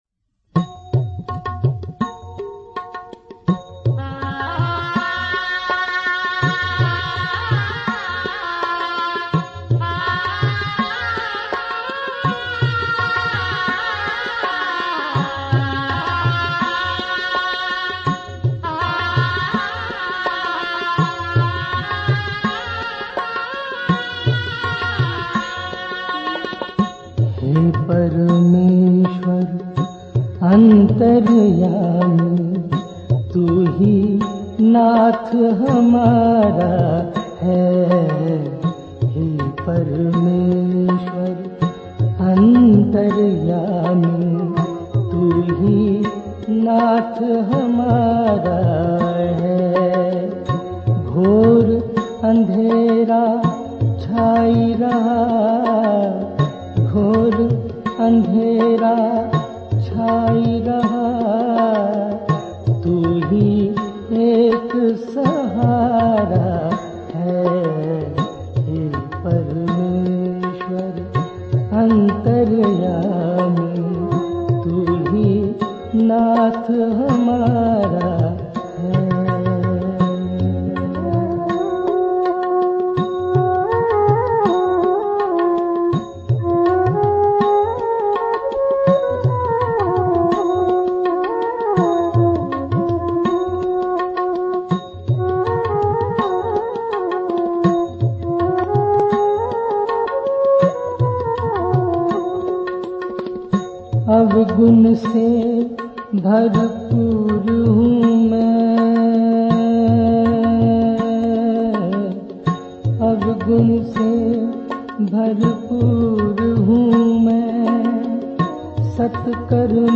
Directory Listing of mp3files/Hindi/Hymns/Fr. George Proksh SVD/ (Hindi Archive)